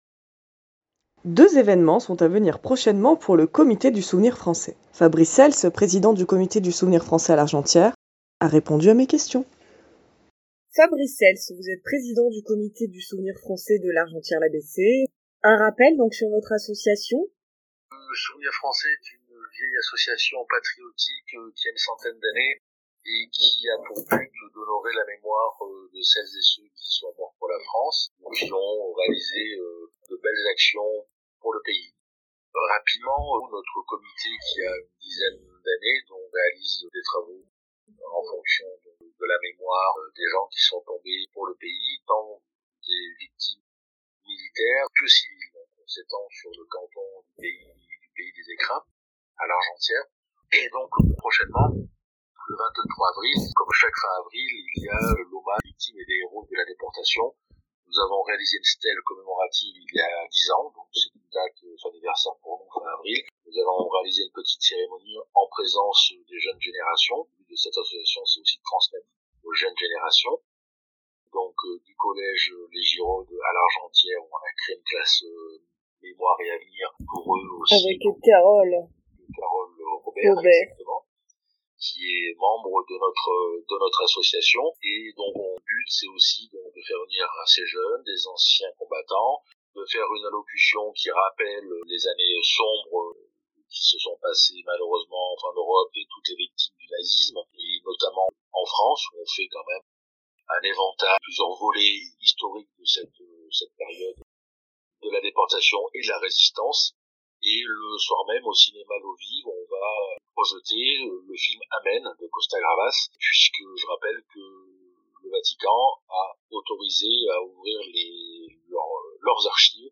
répond aux questions